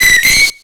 Cri de Rémoraid dans Pokémon X et Y.